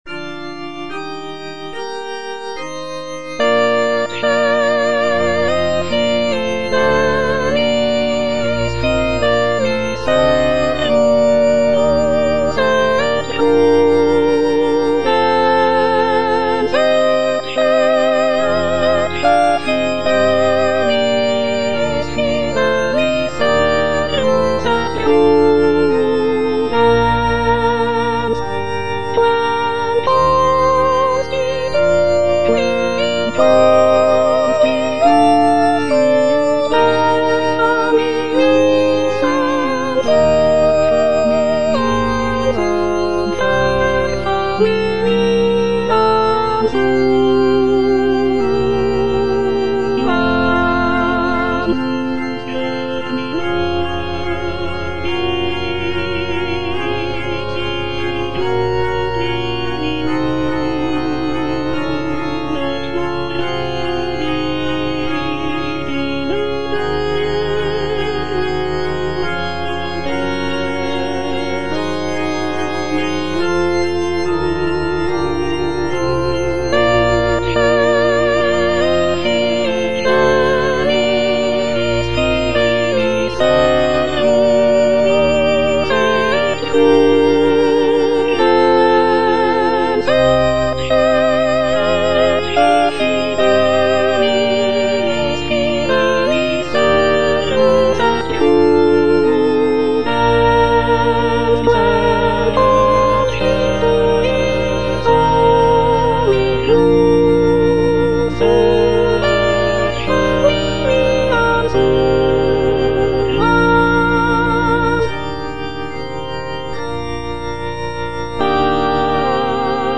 G. FAURÉ - ECCE FIDELIS SERVUS Soprano (Emphasised voice and other voices) Ads stop:
The piece is written for four-part mixed choir and organ, and is based on a biblical text from the Book of Matthew.